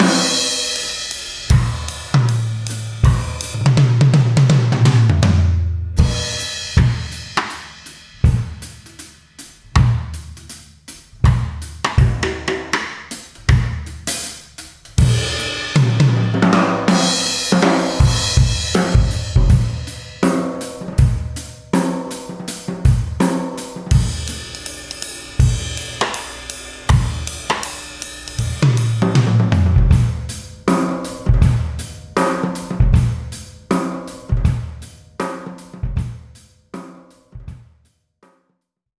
To make it really simply here are three examples of some drums we recorded.
Example 2Reggae Breaks V1 – Lots of medium sized room sound
reggae_breaks_v1_demomix.mp3